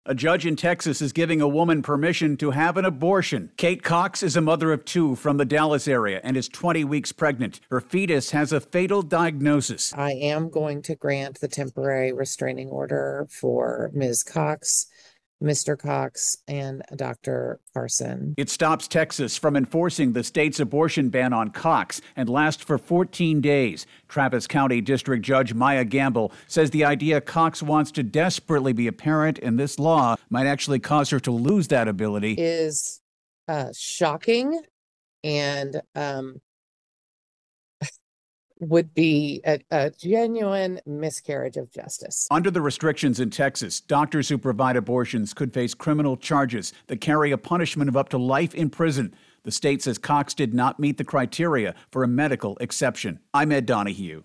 ((Audio courtesy of Travis County 459th District Court))